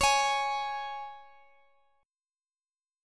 Db5 Chord
Listen to Db5 strummed